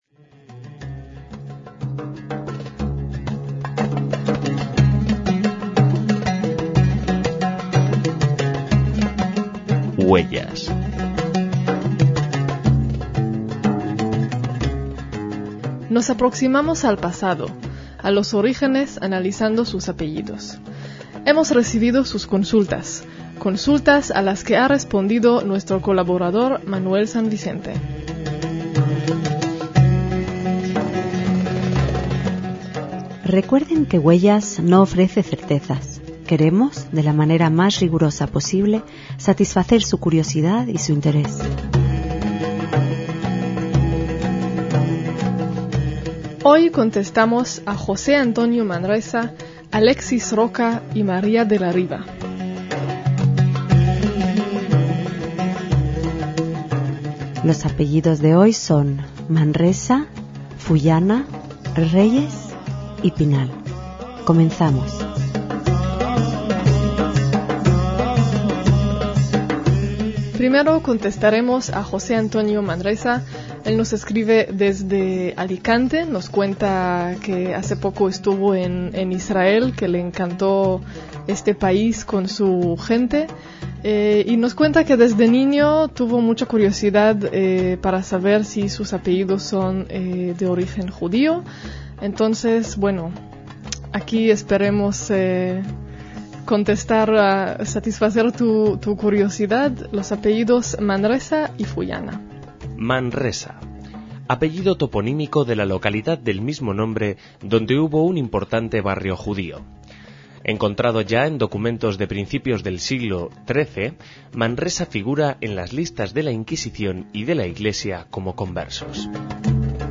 HUELLAS - En la reposición de antiguos programas de nuestro archivo, le toca el turno a los apellidos Manresa, Fullana, Reyes y Pinal, sobre cuyo posible origen judío nos explica